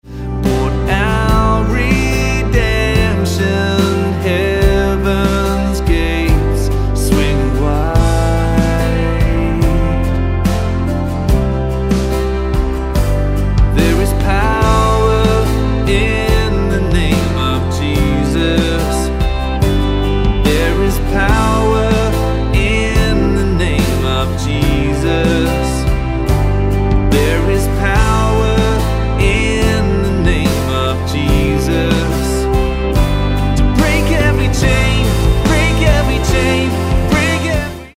Am